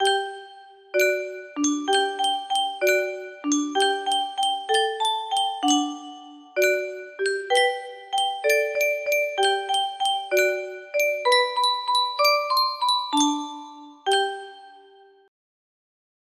Yunsheng Music Box - See-Saw Margery Daw 1997 music box melody
Full range 60